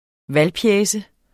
Udtale [ ˈval- ]